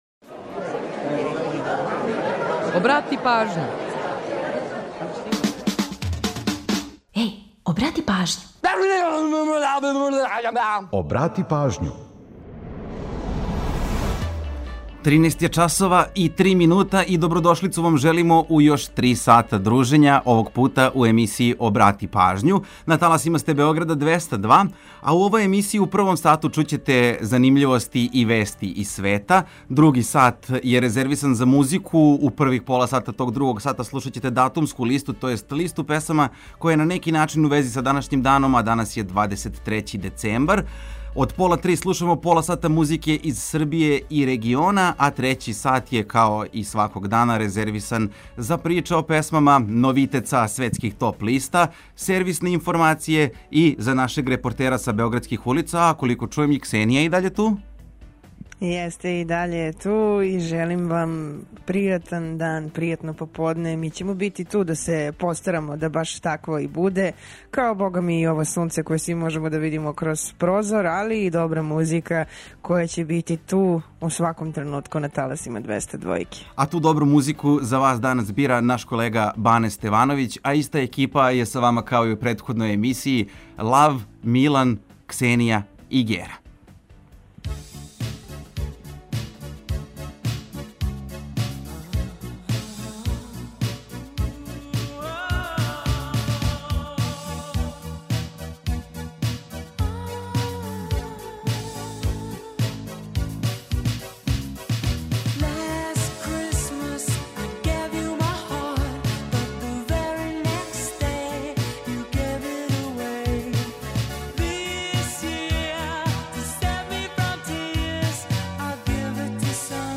У данашњој емисији поред различитих занимљивости слушаћете и датумске песме које нас подсећају на рођендане музичара, као и годишњице објављивања албума, синглова и других значајних догађаја из историје попа и рокенрола.
Ту је и пола сата резервисано само за музику из Србије и региона, а упућујемо вас и на нумере које су актуелне. Чућете и каква се то посебна прича крије иза једне песме, а за организовање дана, ту су сервисни подаци и наш репортер.